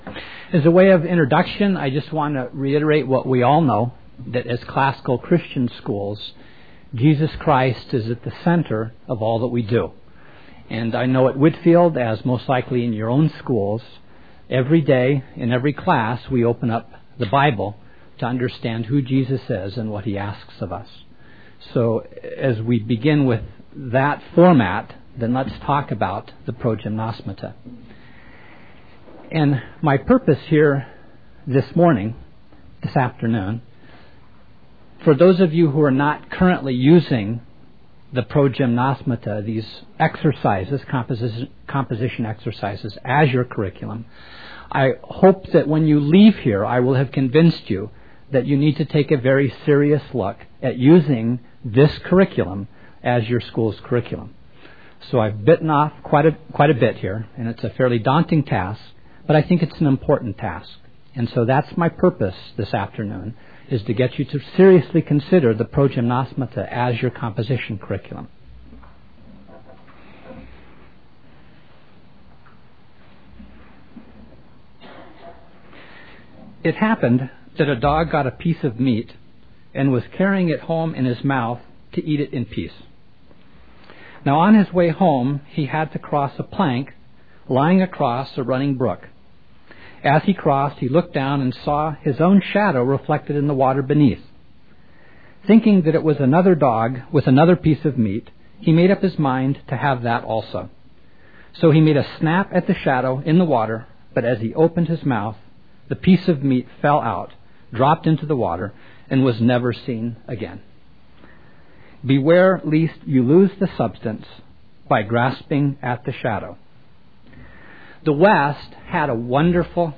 2002 Foundations Talk | 0:45:28 | 7-12, Rhetoric & Composition